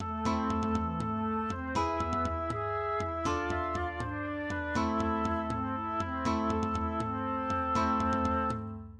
} myMusic = { << \chords { \germanChords \set chordChanges=##t \set Staff.midiInstrument="acoustic guitar (nylon)" s8 | c2.:m | g2. | f2.:m | c2.:m | c2.:m | c2:m } \relative c' { \time 6/8 \partial 8 \tempo 4=120 \key c \minor \clef "treble" \set Staff.midiInstrument="Oboe" c'8 c4 c8 c4 es8 g4 g8 g4 g8 f8 g8 f8 es8 f8 d8 c4 c8 c4 g8 c4 c8 c4.~ c4. r4 \fine } \addlyrics { In Net- ters- quell, zu Pferd so schnell, da rei- tet ein Mül- ler in Samt und Fell, in Net- ters- quell. } \relative c' { \key c \minor \clef "treble" \set Staff.midiInstrument="Flute" c8 es8 c8 g8 c4 es8 g8 d8 g8 b4 g8 aes4. f4. g4. es8 g8 es8 g8 c8 g8 es4.~ es4. r4 } \addlyrics { Von Ta- del ge- führt, der A- del re- giert, so ist's im Schlund wie sich der A- del ge- biert.